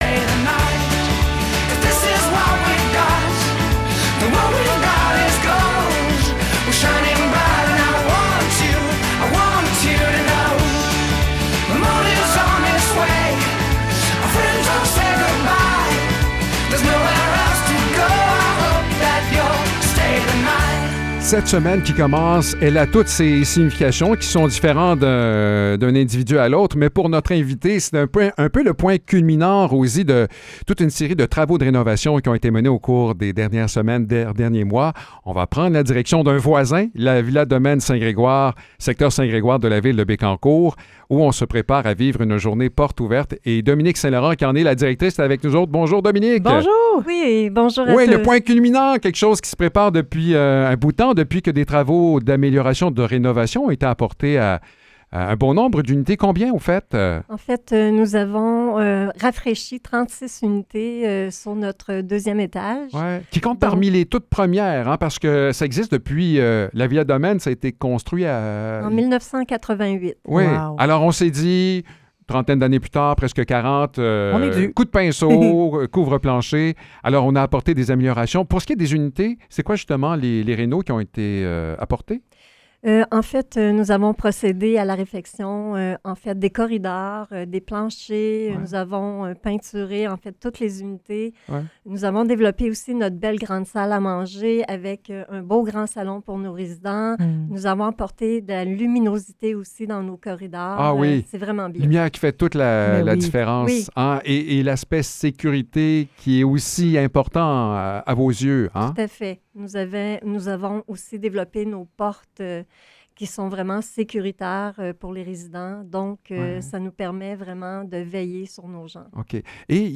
Entrevue pour la Villa Domaine St-Grégoire